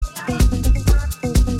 Lounge Other Other